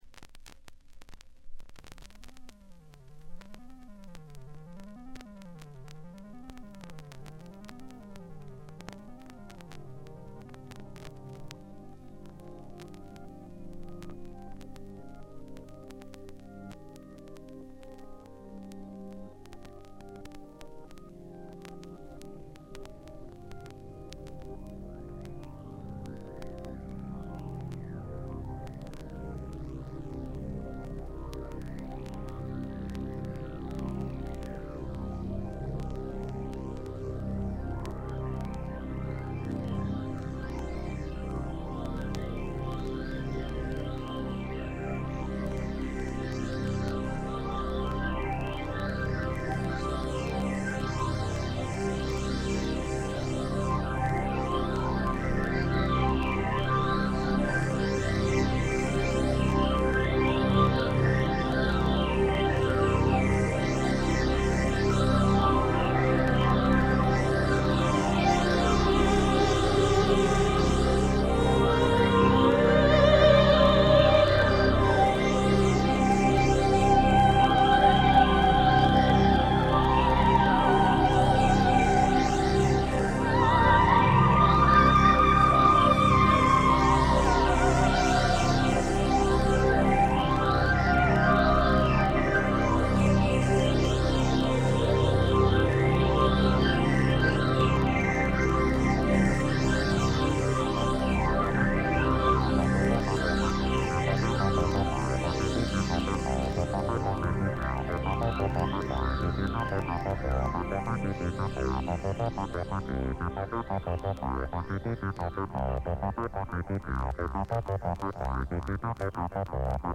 Genre: Rock
A nice play with light minor background noise throughout.